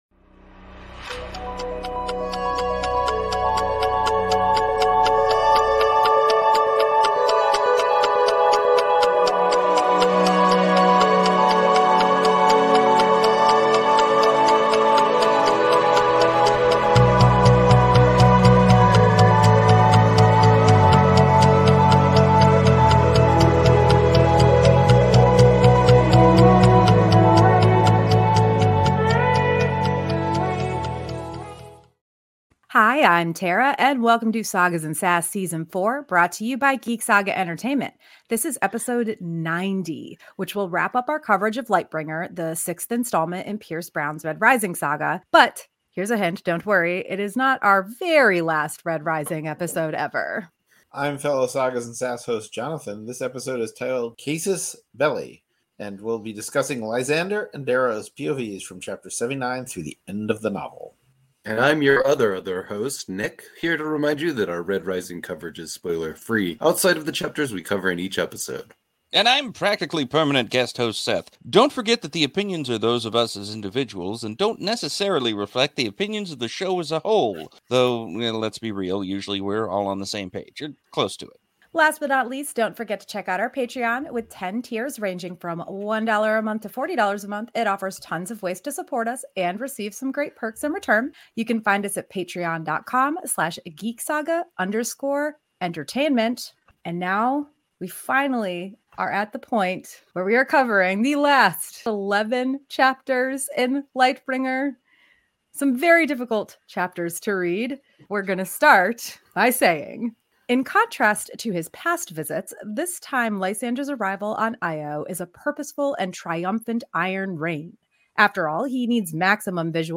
Apologies for being a bit later than expected in releasing this episode – there were some issues with the recording that required extra editing time. While the quality still isn’t as decent as we’d like it to be, it at least turned out better than expected, albeit after a lot of extra work…